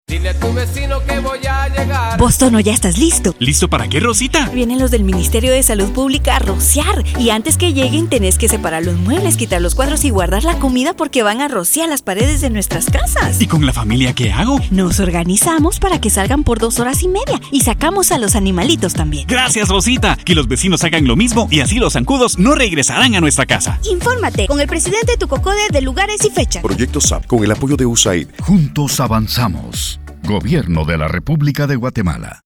• Spot de radio idiomas mayas.